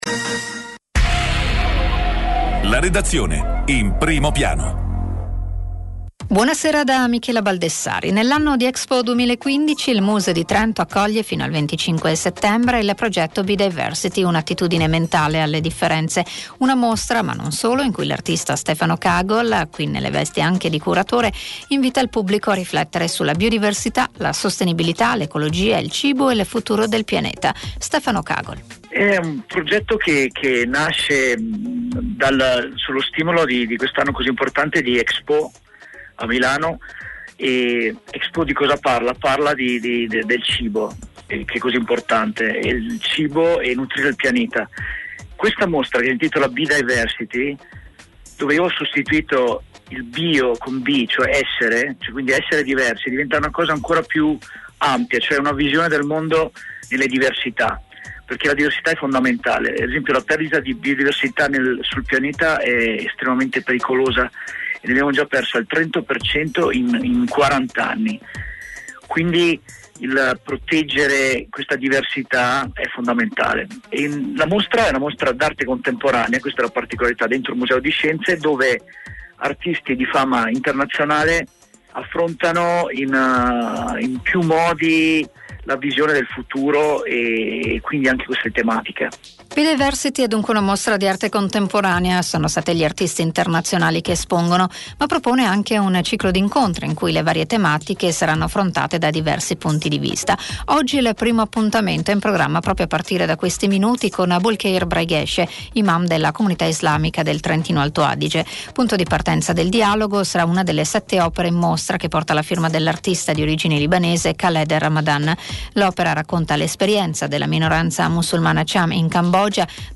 > Radio Interview